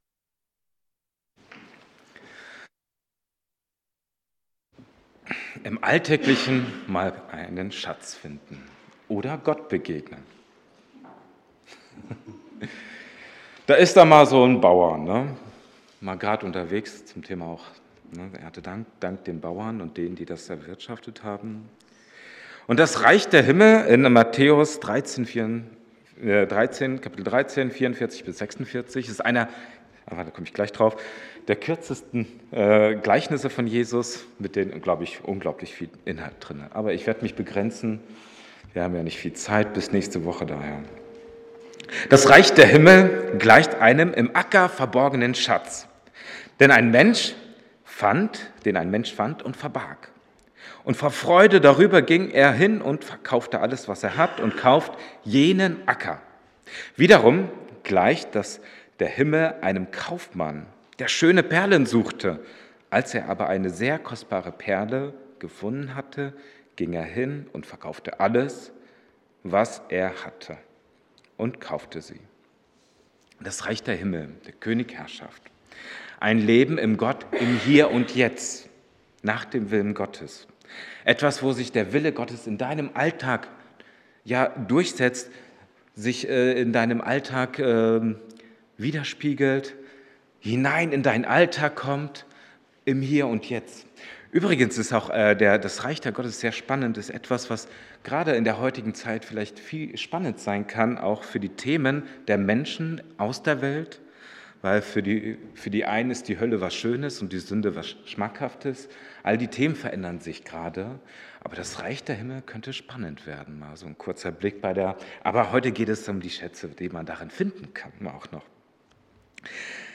Christusgemeinde Siegburg – Predigten Oktober 2025